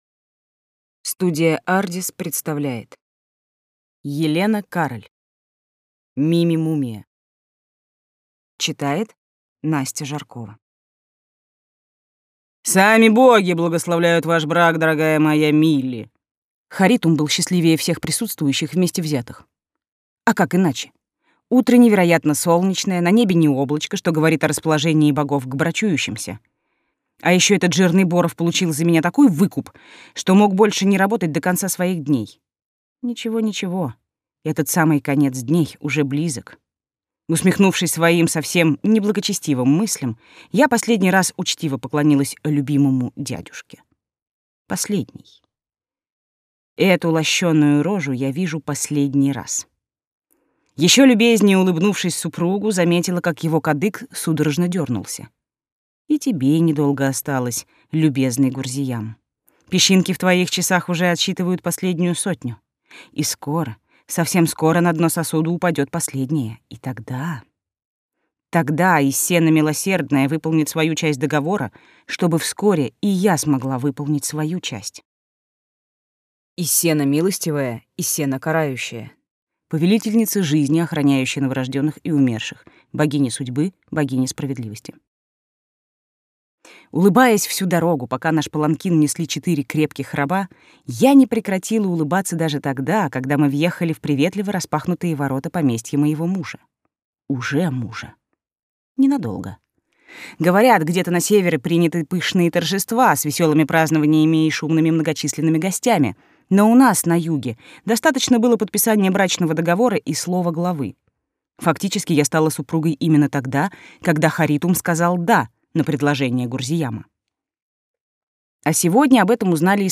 Аудиокнига Мими – мумия | Библиотека аудиокниг